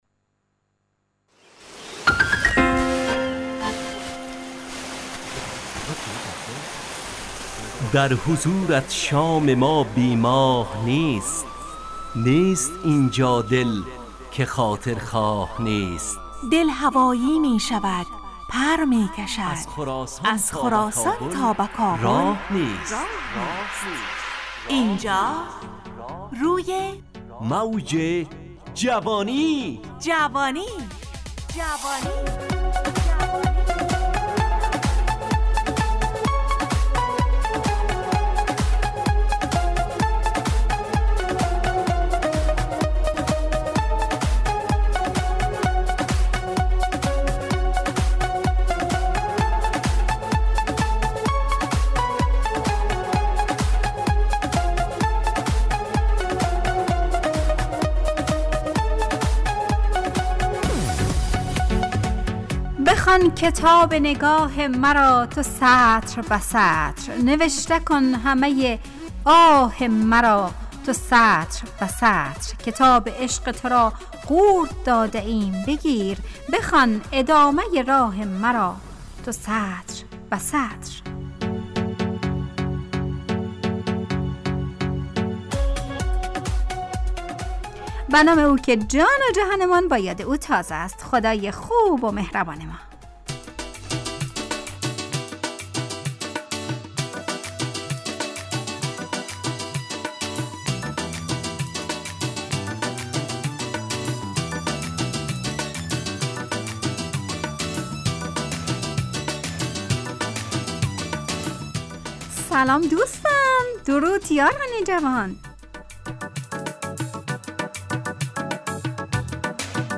همراه با ترانه و موسیقی مدت برنامه 55 دقیقه . بحث محوری این هفته (سواد) تهیه کننده